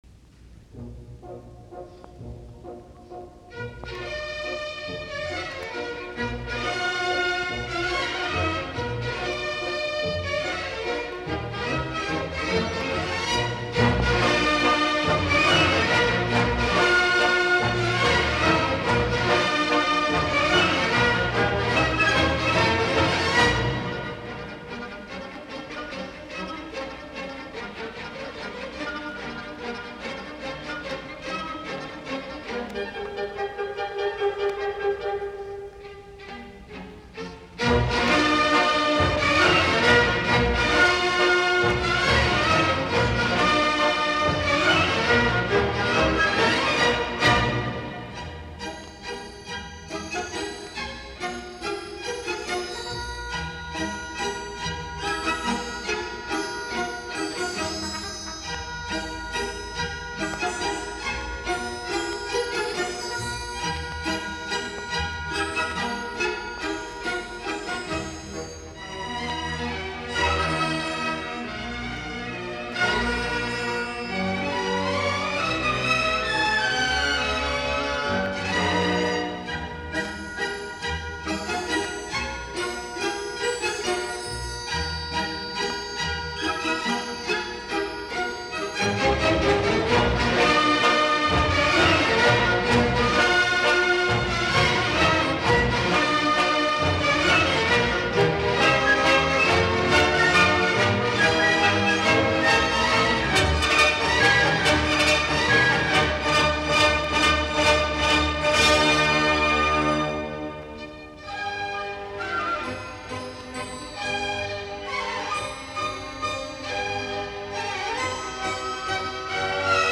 Soitinnus: Ork.